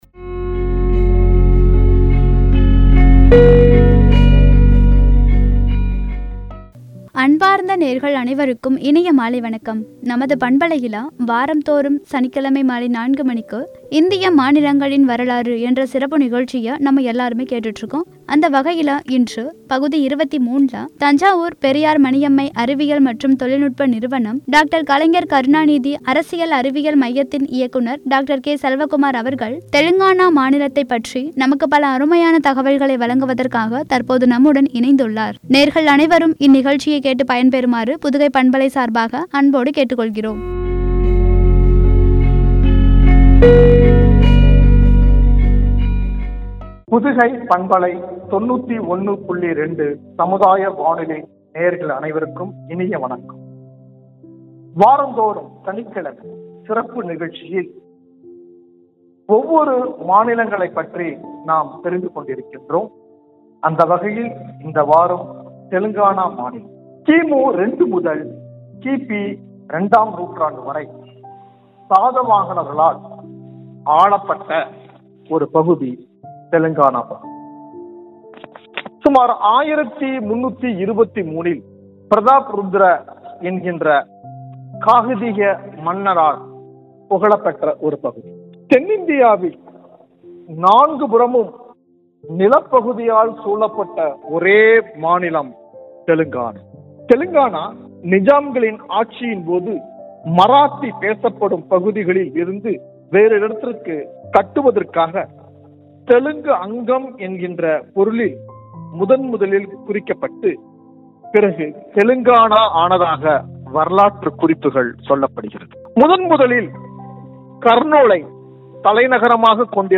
வழங்கிய உரை.